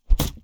Close Combat Attack Sound 28.wav